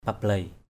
/pa-bleɪ/